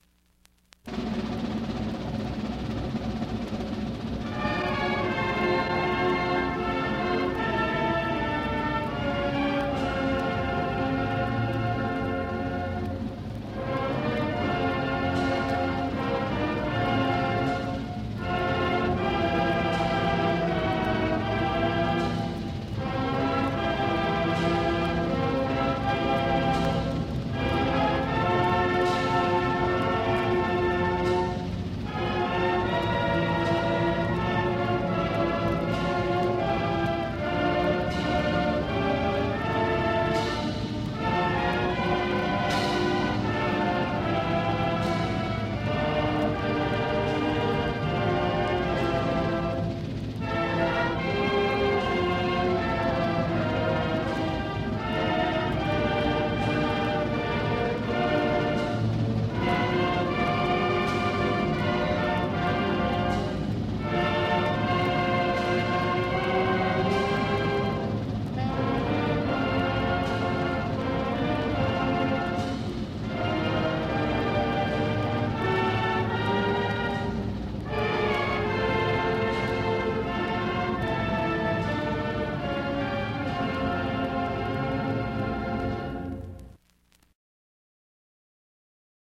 Click on the link below to hear the 1961 Band playing the Alma Mater: